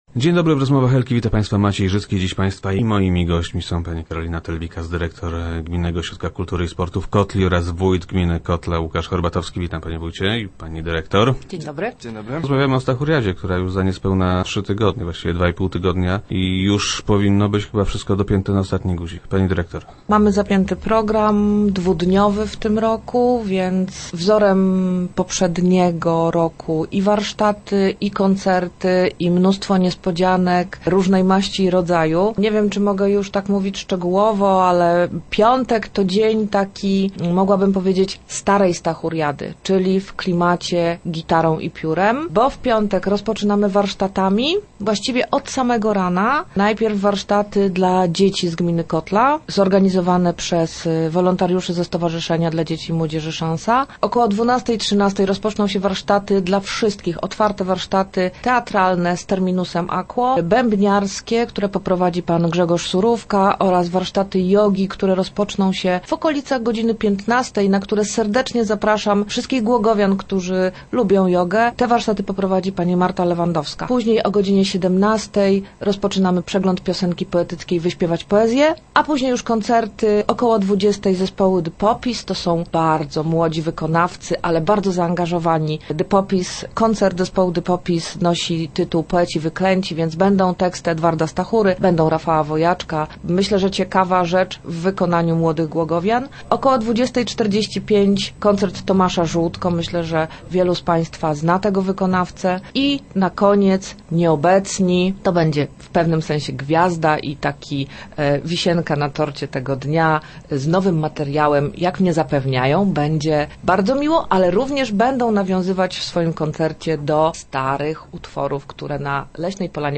04.06.2012. Radio Elka